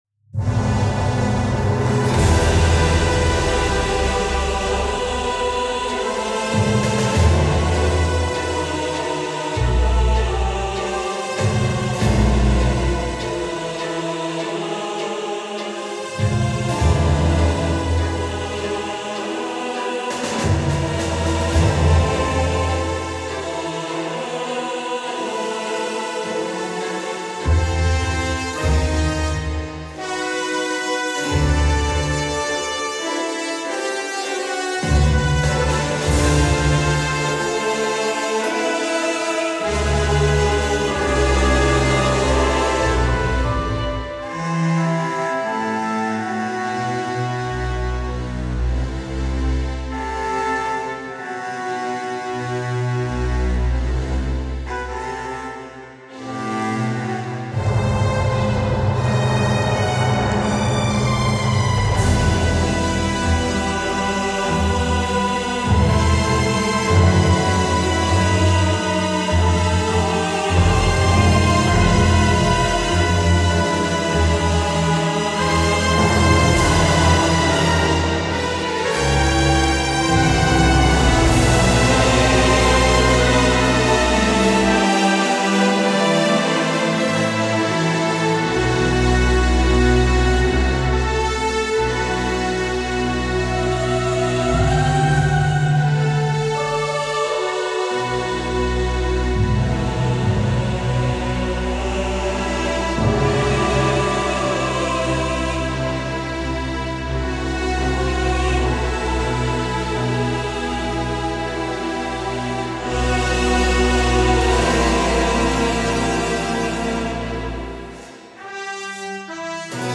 Genre : Soundtrack.